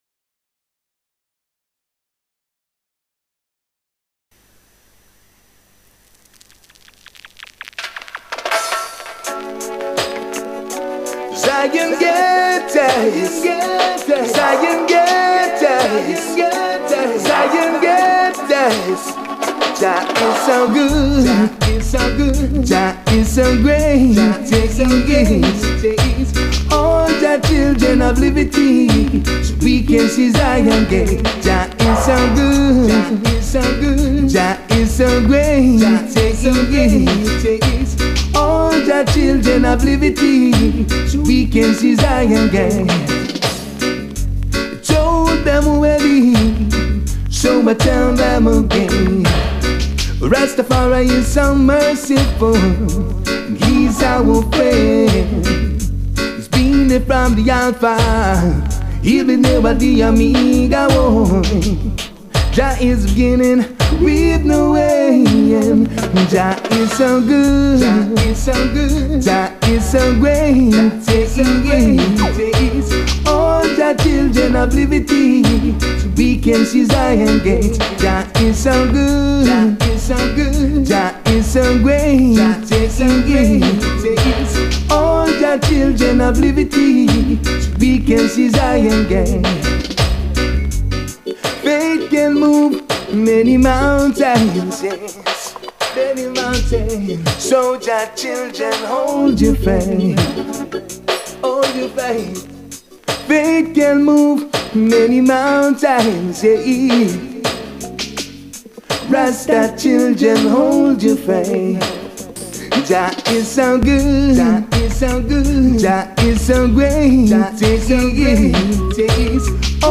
Record @ L'OUSTALET (fr)